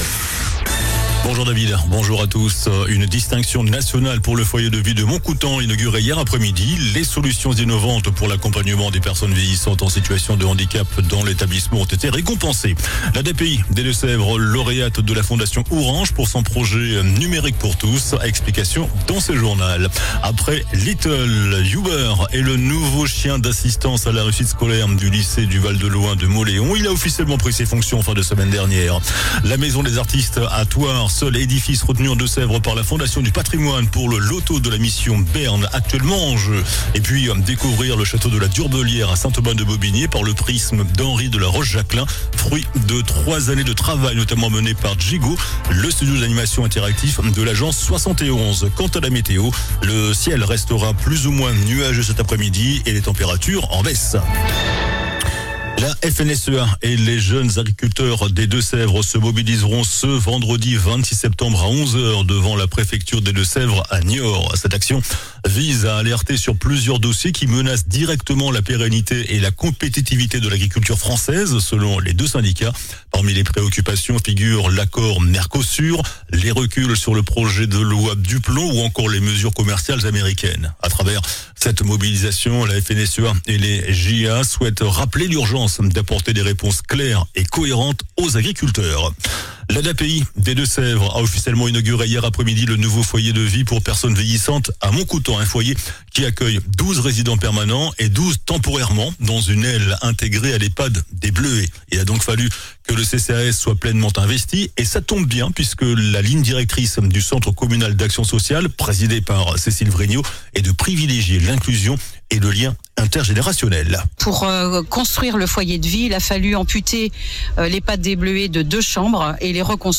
JOURNAL DU MARDI 23 SEPTEMBRE ( MIDI )